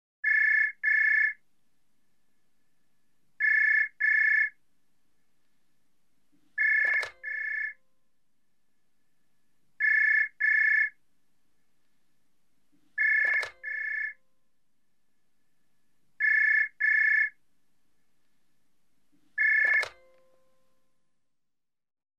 ringlong.mp3